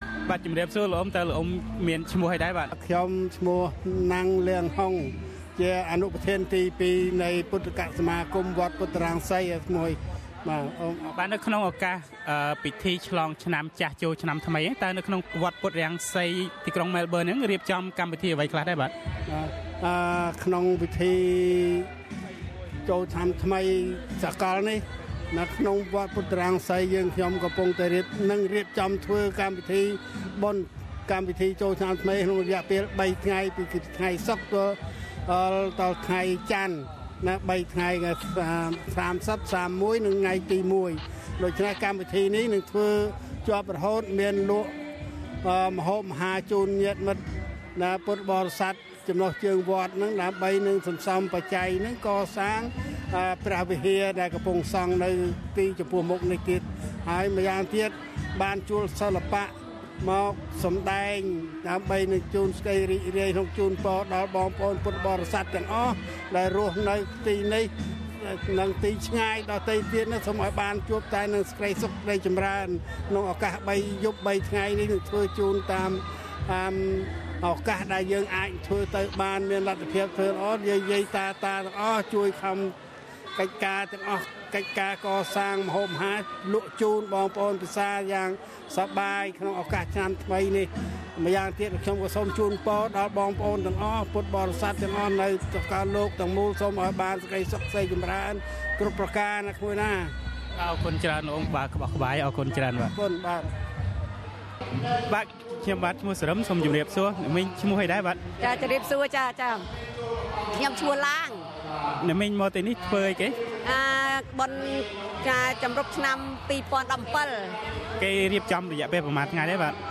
ពាក្យពេចន៍ជូនពររបស់ពលរដ្ឋខ្មែរអូស្រ្តាលីក្នុងឱកាសពិធីបុណ្យឆ្លងឆ្នាំសកលនៅវត្តពុទ្ធរង្សីម៉ែលប៊ិន។